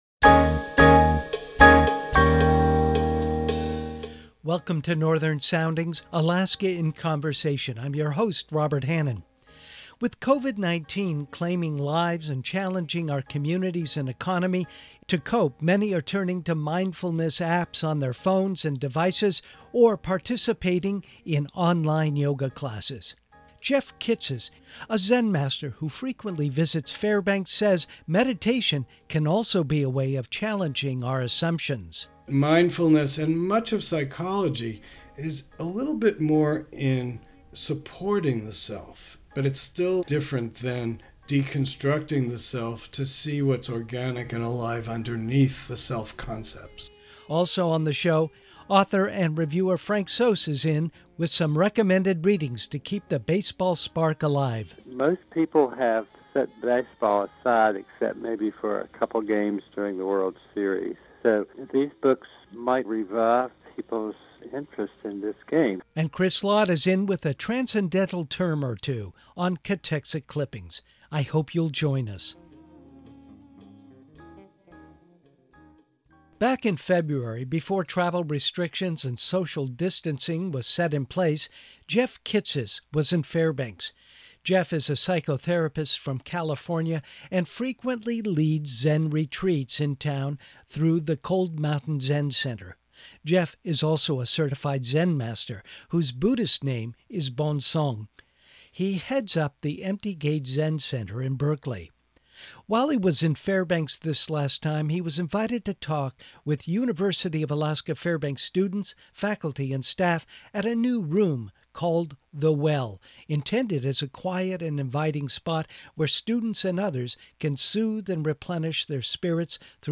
While he was in Fairbanks this last time he was invited to talk with University of Alaska Fairbanks students, faculty and staff at a new room called The Well, intended as a quiet and inviting spot where students and others can sooth and replenish their spirits through meditation or other contemplative practices.